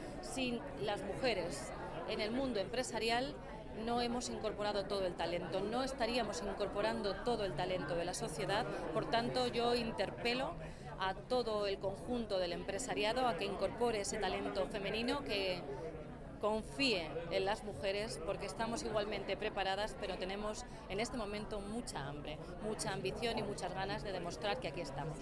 blanca_fdez-_gala_amepap.mp3